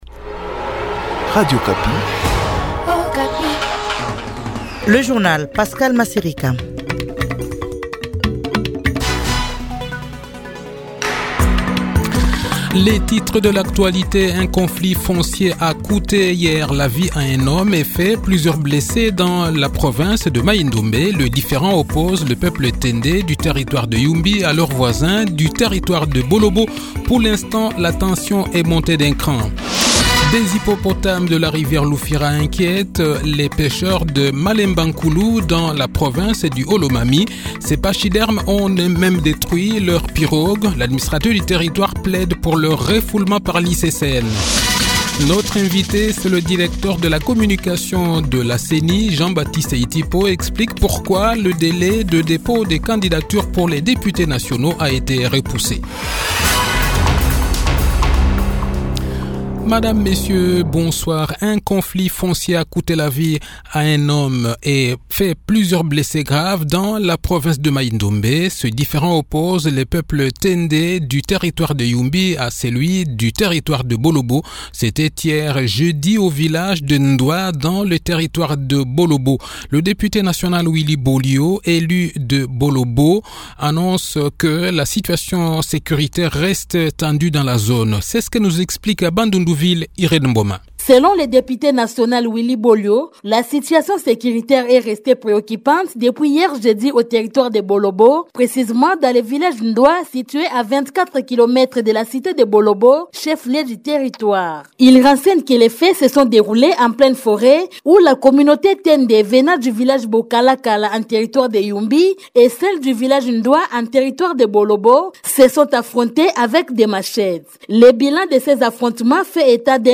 Le journal de 18 h, 21 Juillet 2023